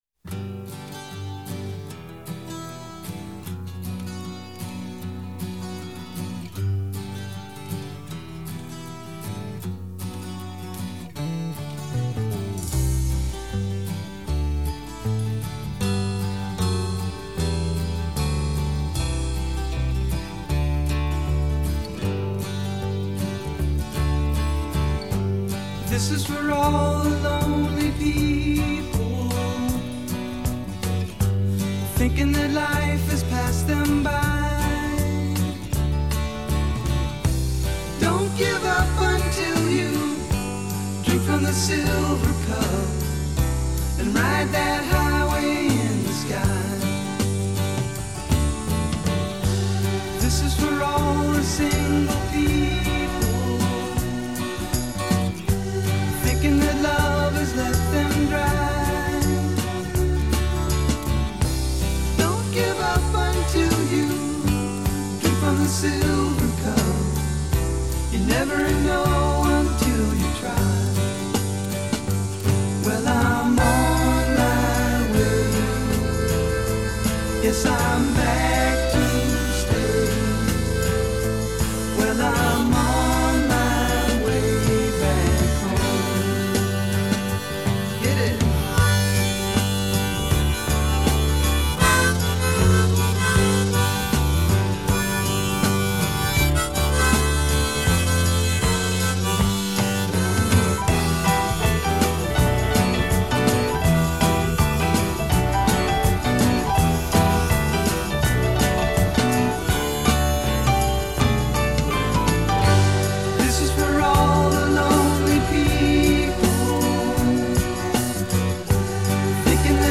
again a lot of hiss.. maybe that’s the thing 😀